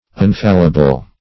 Unfallible \Un*fal"li*ble\, a.